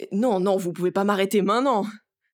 VO_ALL_Interjection_09.ogg